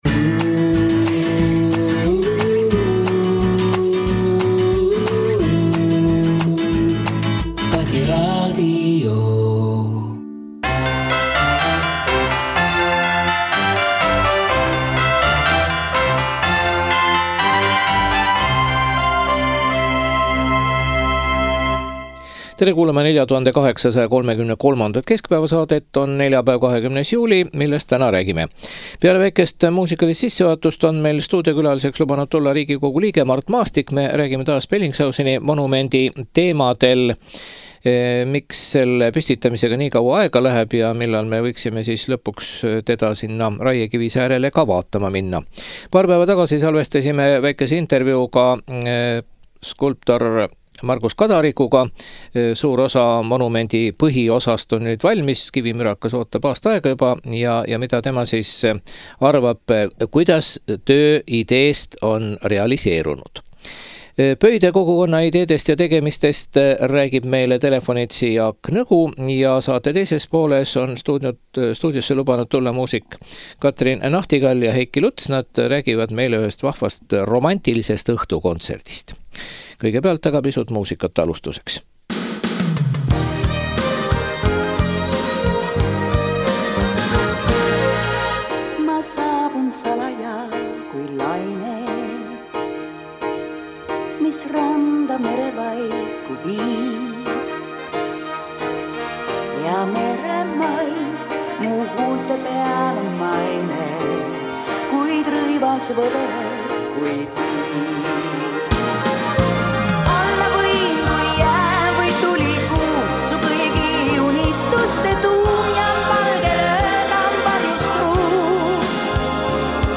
Stuudios on Riigikogu liige Mart Maastik, kes mures Bellingshauseni monumendi püstitamise venimise pärast.